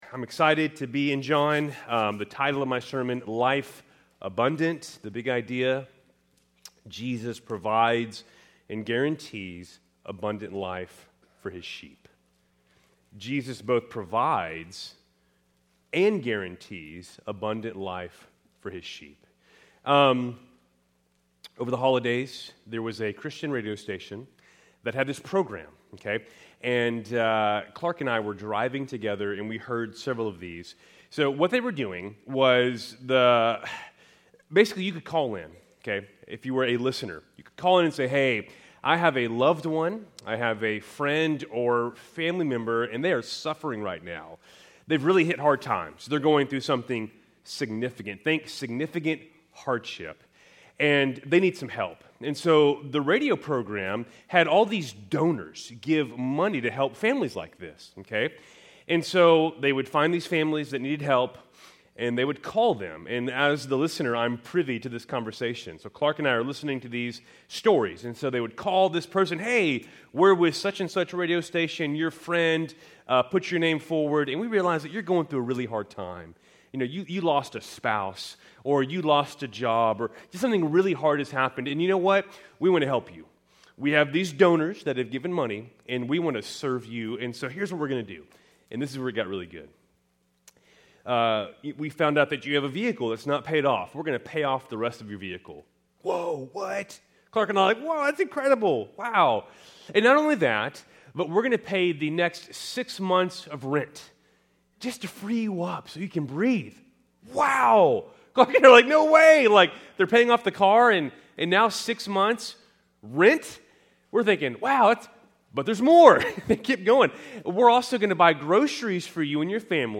Keltys Worship Service, January 12, 2025